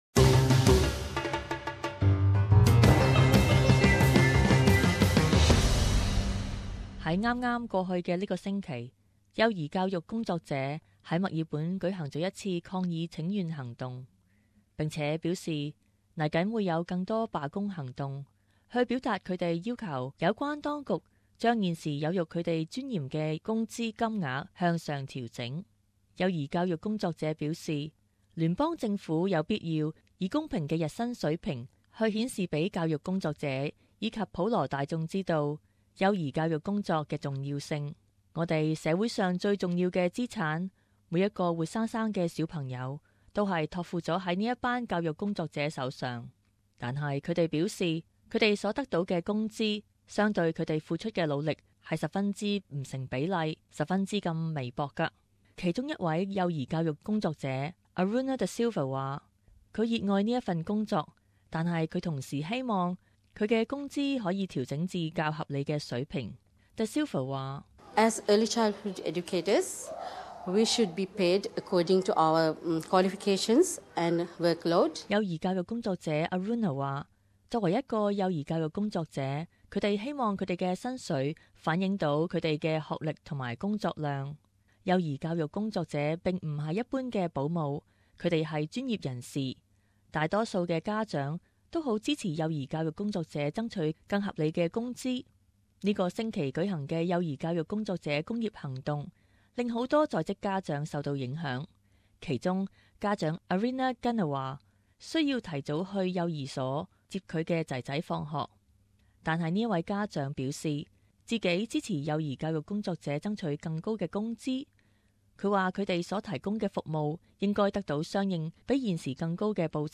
【時事報導】 幼教工作者爭取更好待遇